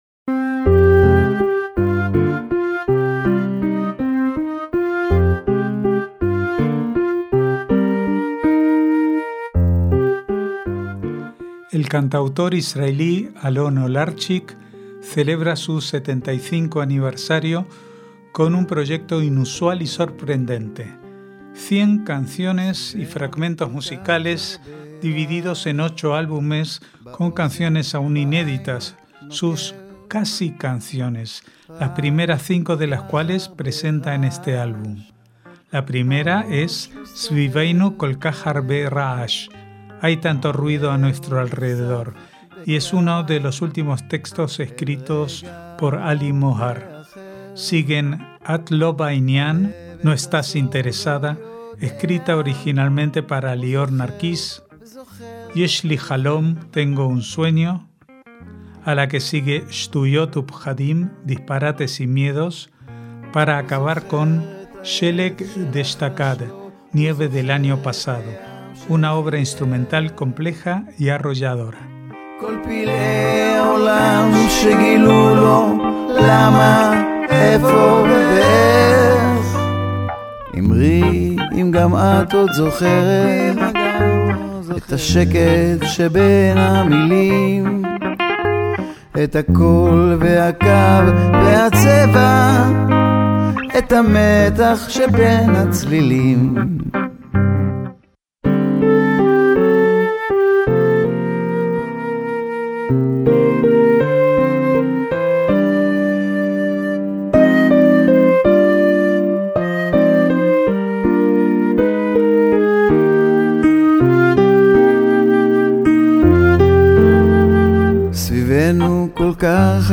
MÚSICA ISRAELÍ
una obra instrumental compleja y arrolladora